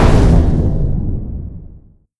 explosionbig.ogg